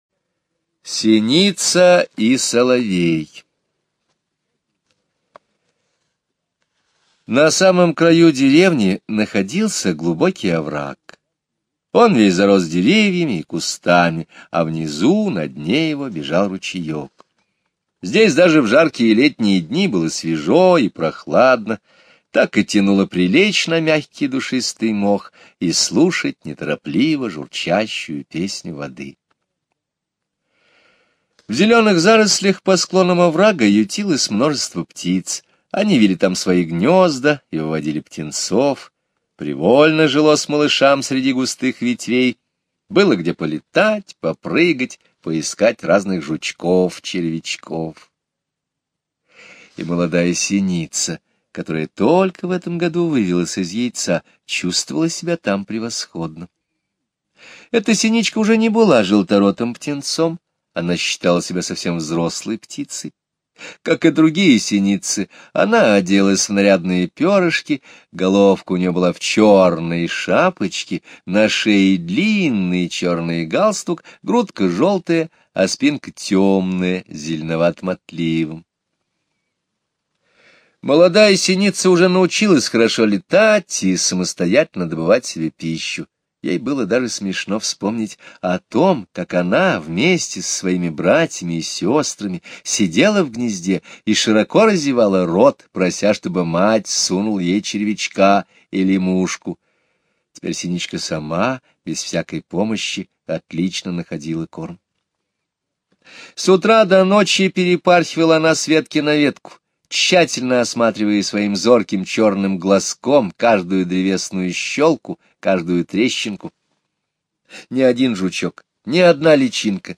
Слушайте аудио рассказ "Синица и соловей" Скребицкого Г. онлайн на сайте Мишкины книжки.